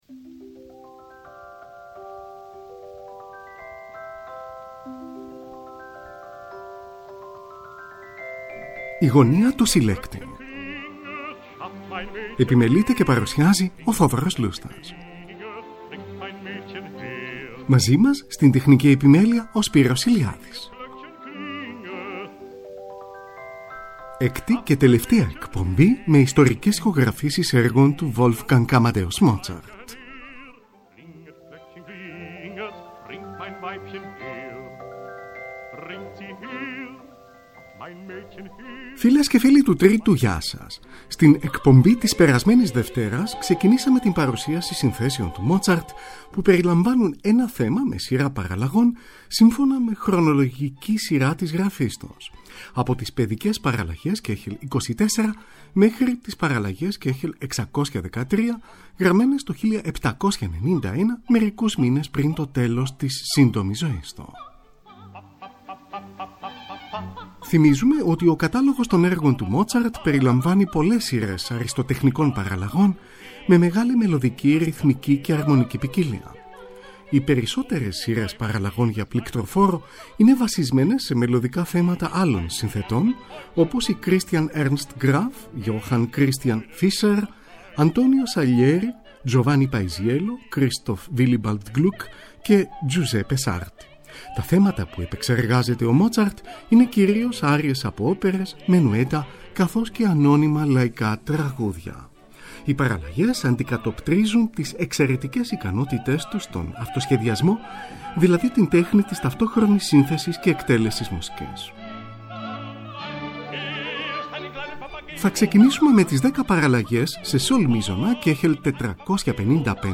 ΙΣΤΟΡΙΚΕΣ ΗΧΟΓΡΑΦΗΣΕΙΣ ΕΡΓΩΝ ΤΟΥ WOLFGANG AMADEUS MOZART (ΕΚΤΟ ΜΕΡΟΣ)
Δέκα παραλλαγές για πληκτροφόρο, πάνω σε ένα θέμα του Gluck, Κ. 455.
Οκτώ παραλλαγές για πληκτροφόρο, πάνω στο “Come un’ agnello” του Sarti, Κ. 460.